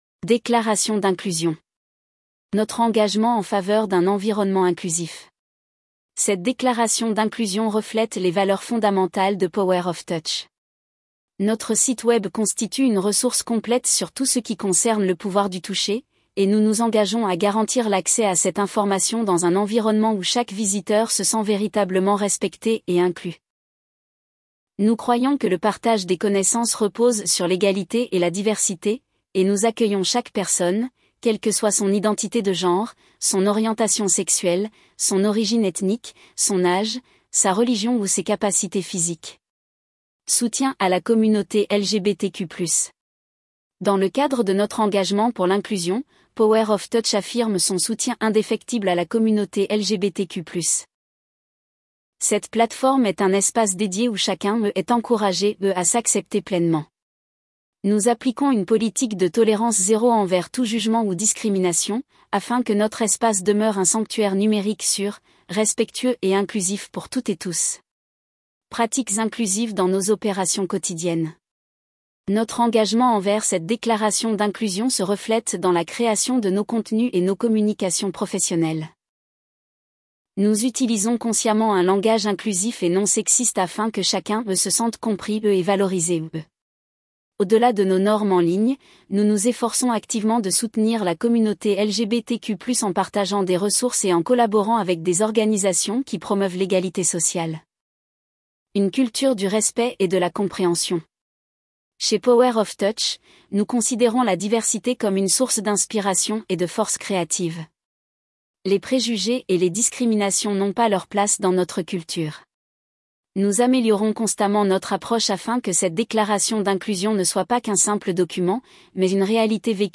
mp3-text-to-voice-declaration-dinclusion-power-of-touch.mp3